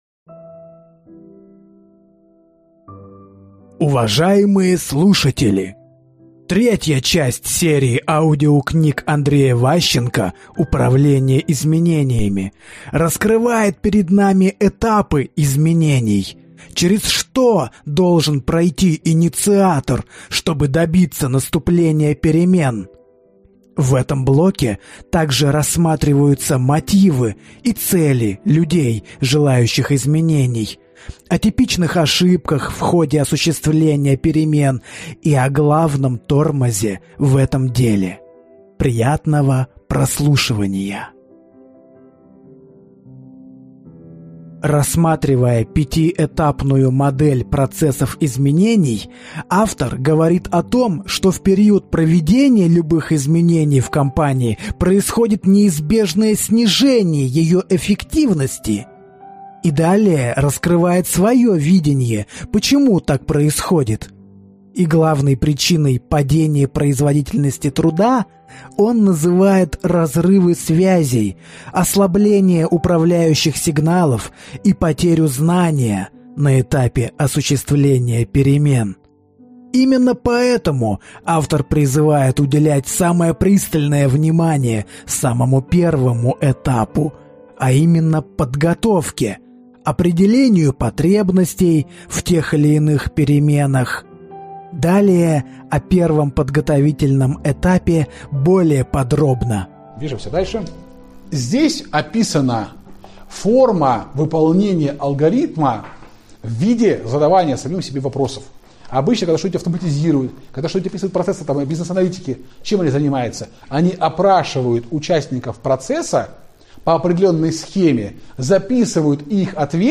Аудиокнига Управление изменениями. Российская практика. Часть 3 | Библиотека аудиокниг